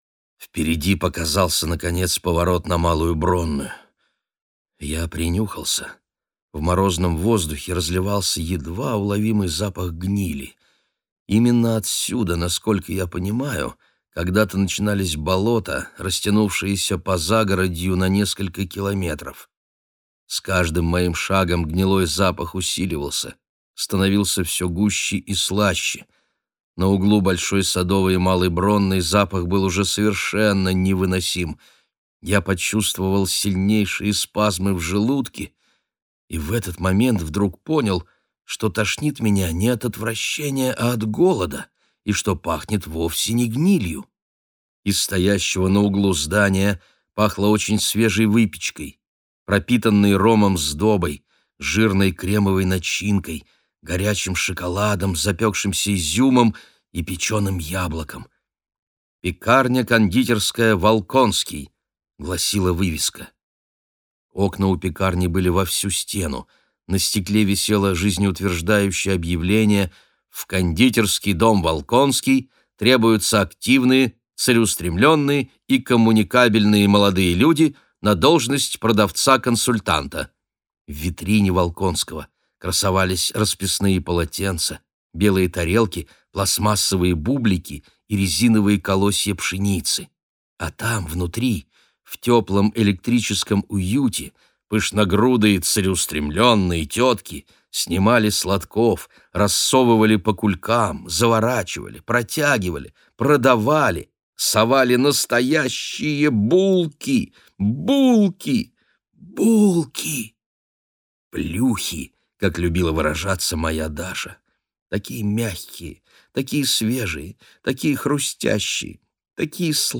Аудиокнига Резкое похолодание. Зимняя книга | Библиотека аудиокниг